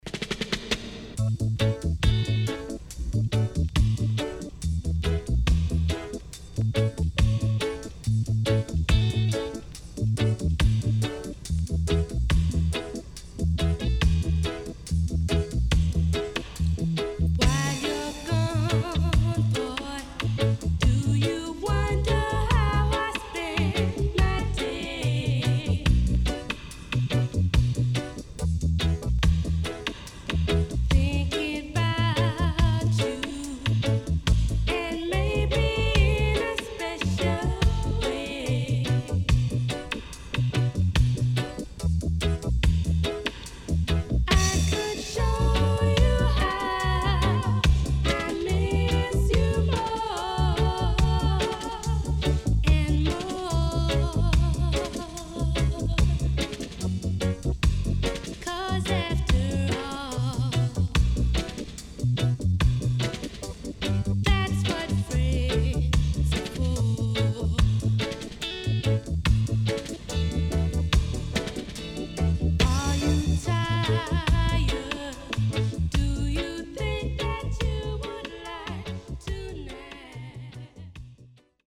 Sweet Lovers Vocal.W-Side Good
SIDE A:少しチリノイズ入りますが良好です。